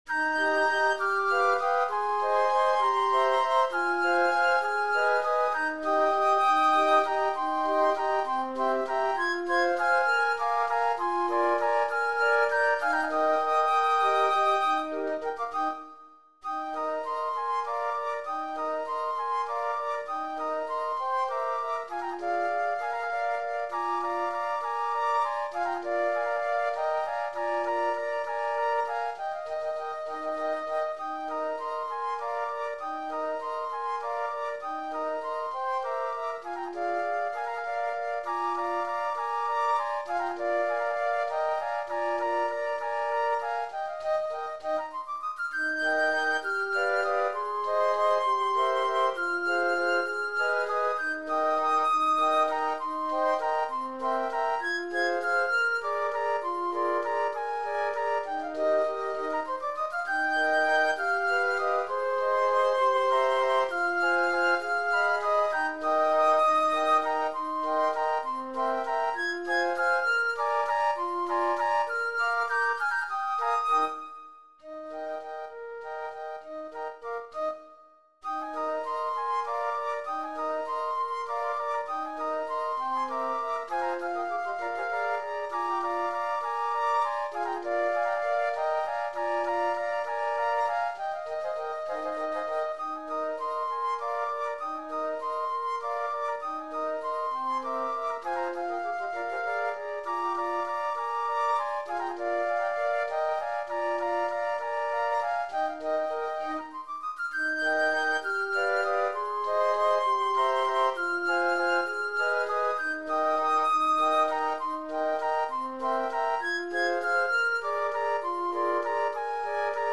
Composer: Mexican Folk Song
Voicing: Flute Sextet